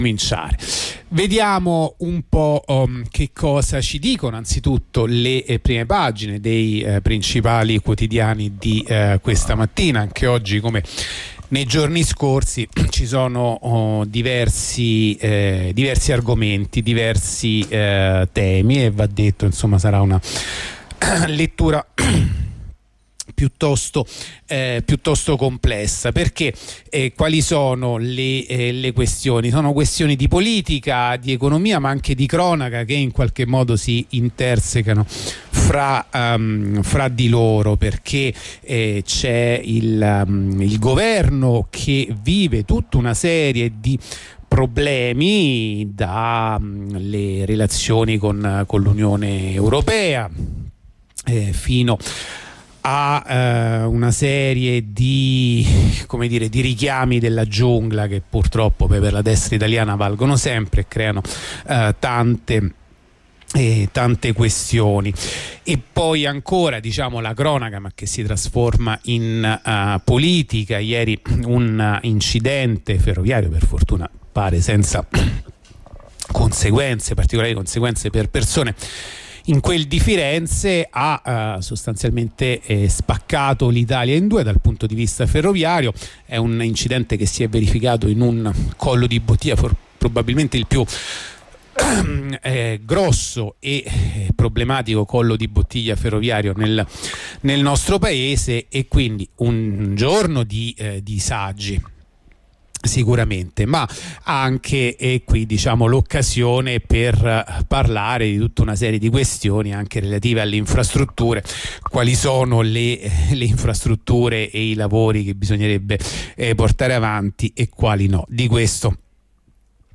La rassegna stampa di venerdì 21 aprile 2023
La rassegna stampa di radio onda rossa andata in onda venerdì 21 aprile 2023